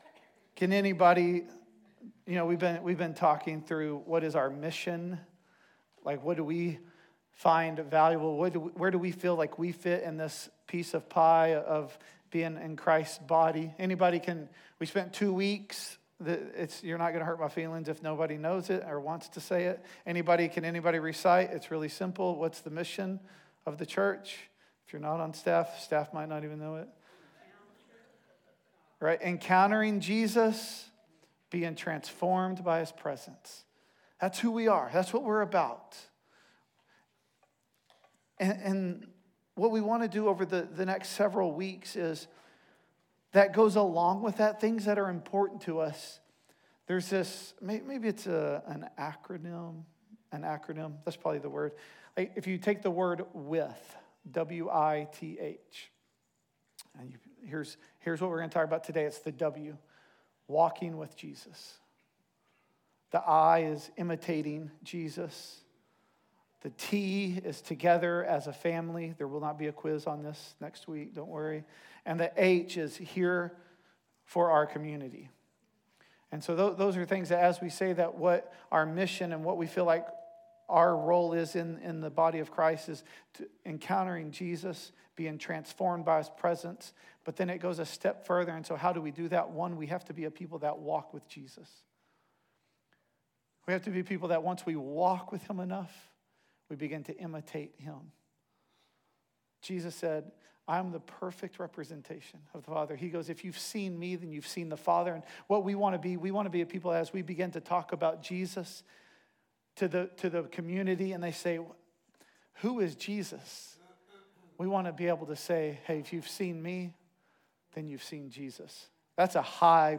The Gathering at Adell Audio Sermons WITH - Walking with Jesus Part 1 Play Episode Pause Episode Mute/Unmute Episode Rewind 10 Seconds 1x Fast Forward 30 seconds 00:00 / 00:47:59 Subscribe Share RSS Feed Share Link Embed